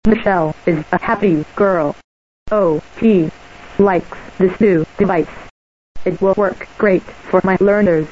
with built-in Text-to-Speech (TTS) and speaker!
You can hear the Fusion's TTS through included headphones or its great built-in speaker!